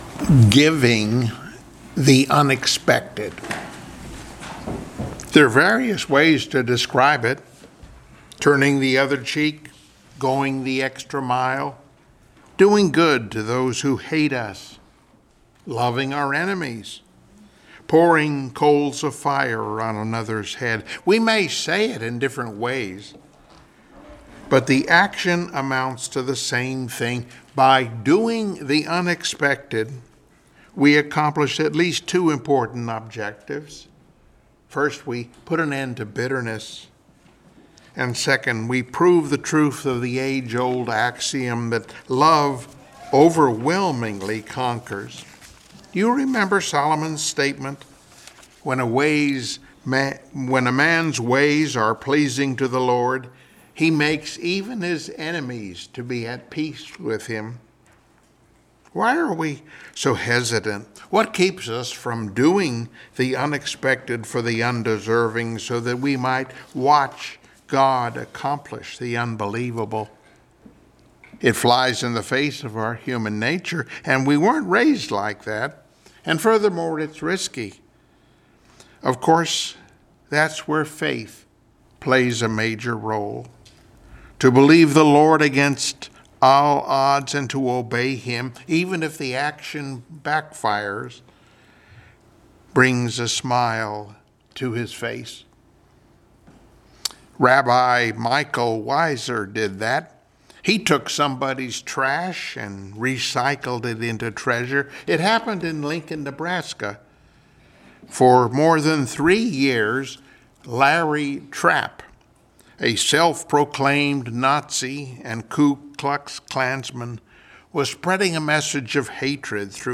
Service Type: Sunday Morning Worship Topics: Forgiving One Another , Judah's Exploits , Vengeance is God's